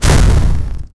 blade-cuts-air-with-metal-zf4p2r5m.wav